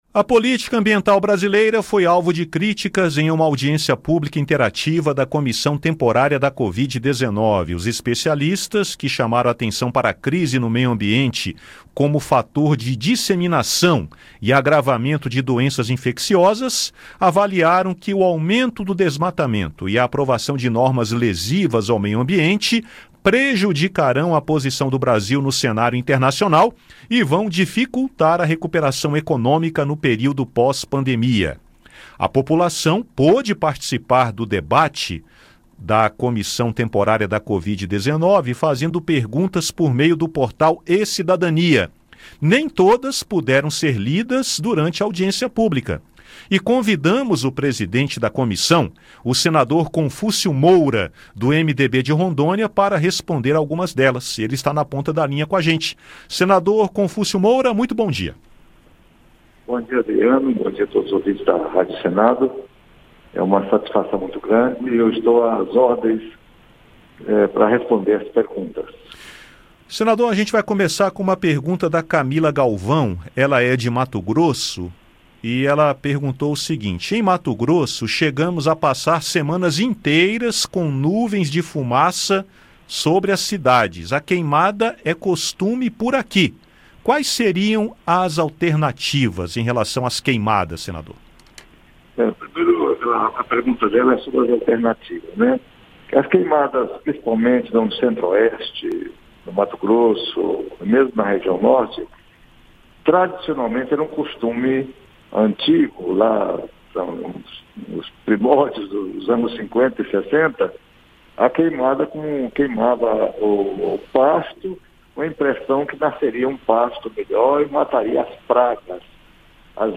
Por meio do portal e-Cidadania, a população pôde participar do debate com perguntas, mas algumas não foram respondidas durante a audiência. Convidamos o presidente da Comissão, senador Confúcio Moura (MDB-RO), para responder algumas delas.